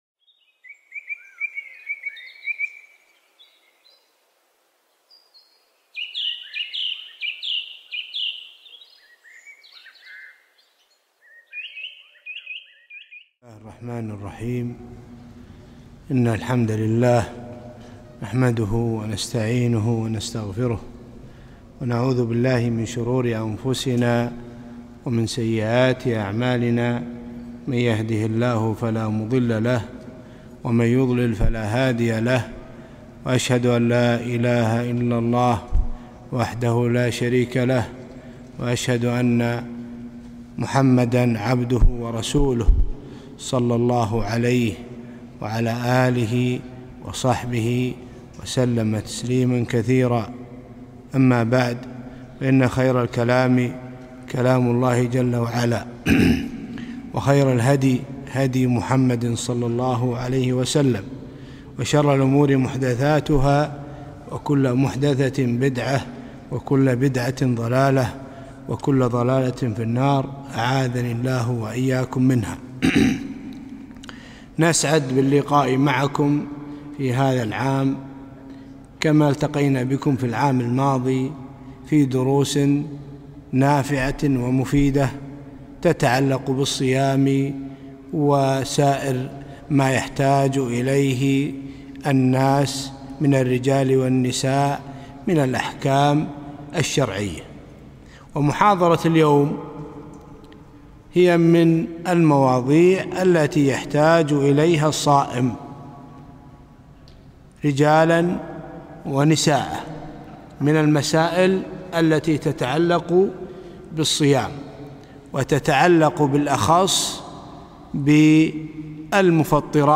محاضرة - المفطرات قديماً وحديثاً